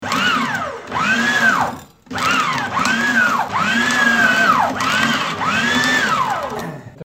Звуки мясорубки
6. Функционирующая мясорубка